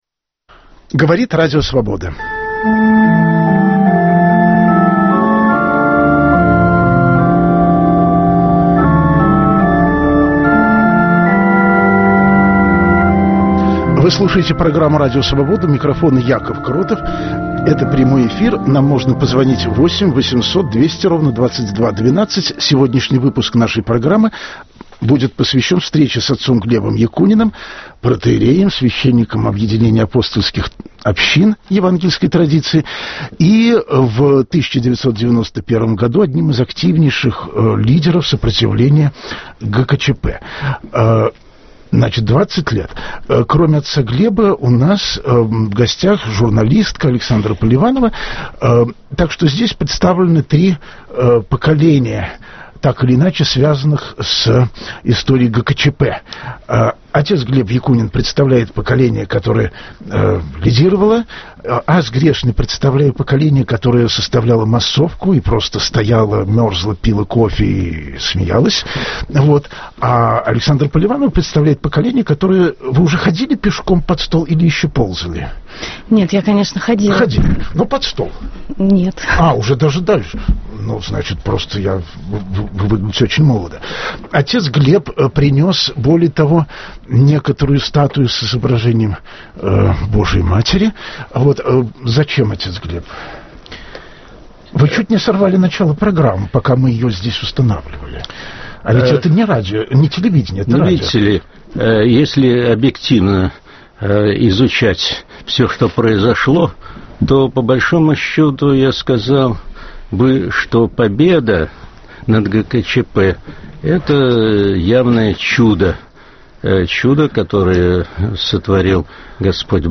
Гостем программы будет священник Глеб Якунин, один из активнейших участников событий августа 1991 года, и представители того поколения верующих, для которых путч - далёкое прошлое сомнительной важности.